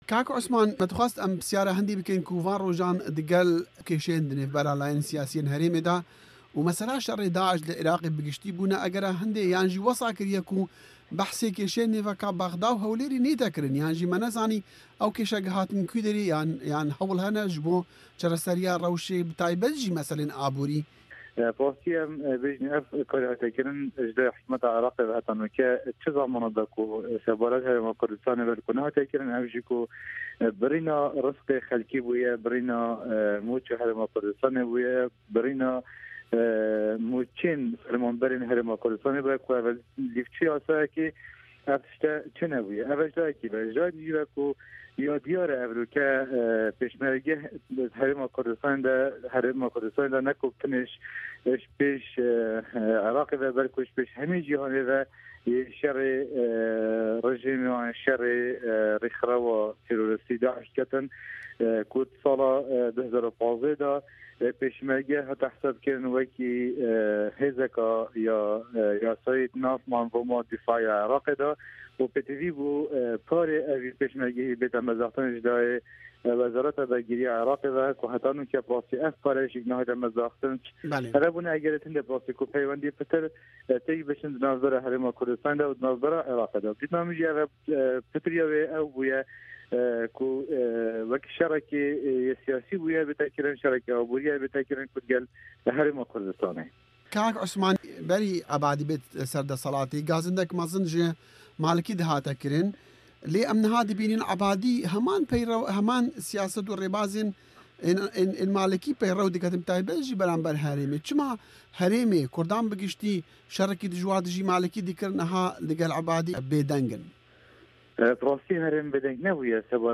Di faila deng de guhdariya temamî axiftinên parlementer Osman Tahir Doskî bike: